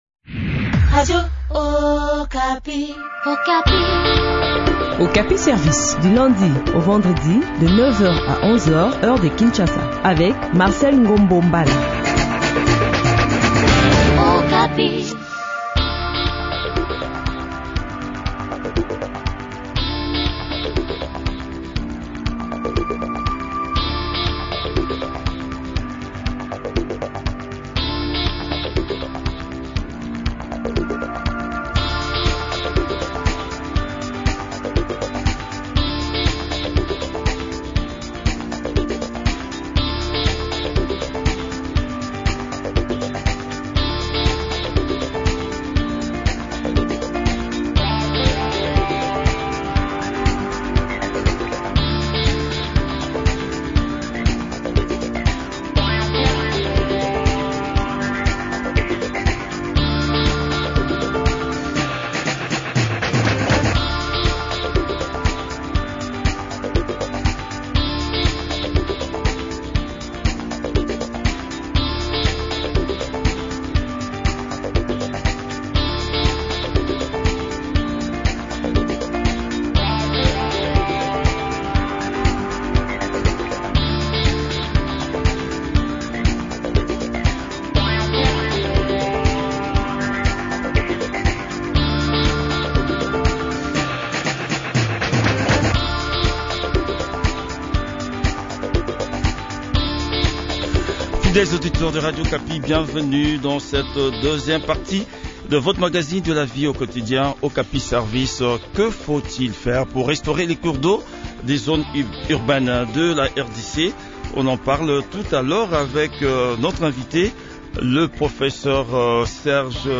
chercheur sur les questions de l'environnement et risques naturels a aussi participé à cette interview.